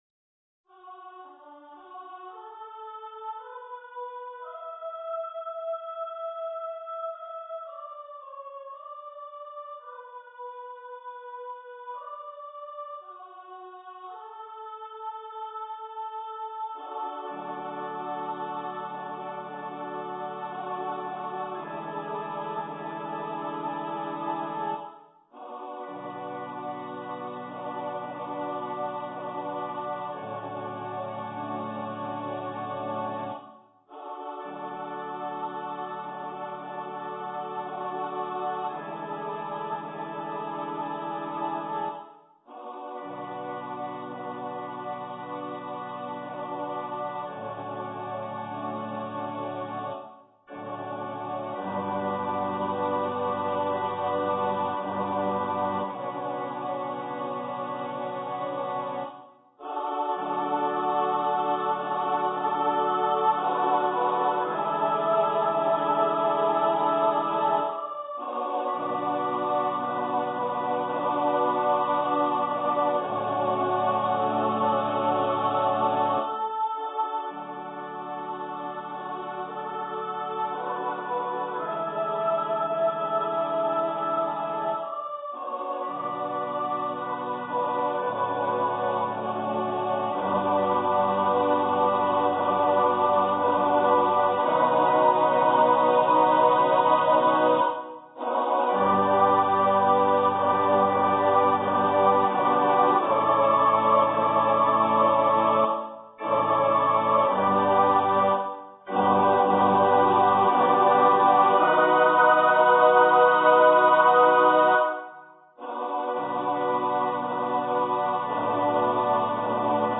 for female voice choir
The organ part is optional but preferable.
Choir - 4 part upper voices